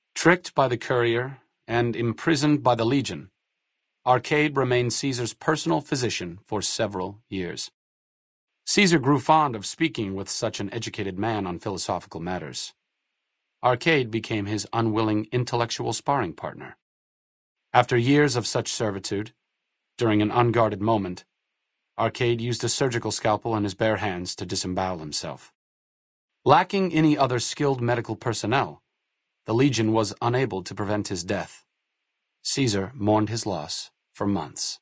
Category:Fallout: New Vegas endgame narrations Du kannst diese Datei nicht überschreiben. Dateiverwendung Die folgenden 2 Seiten verwenden diese Datei: Arcade Gannon Enden (Fallout: New Vegas) Metadaten Diese Datei enthält weitere Informationen, die in der Regel von der Digitalkamera oder dem verwendeten Scanner stammen.